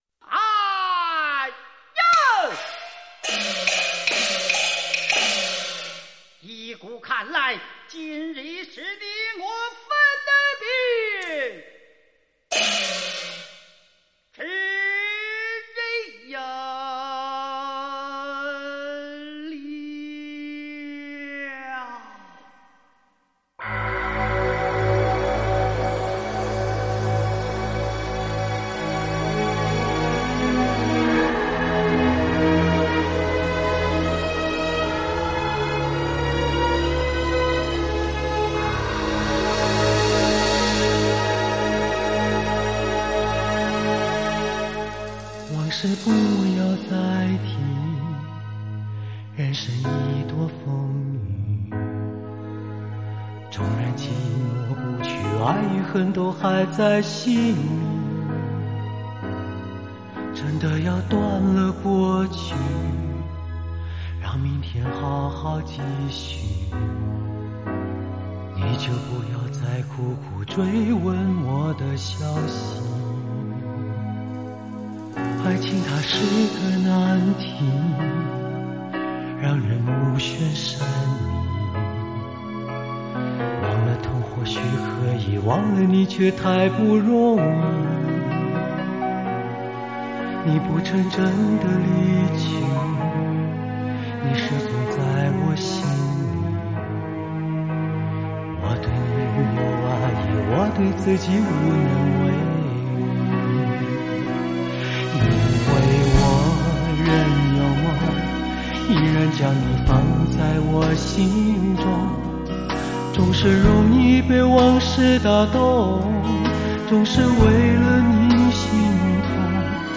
LP黑胶 精装10碟 HI-FI音质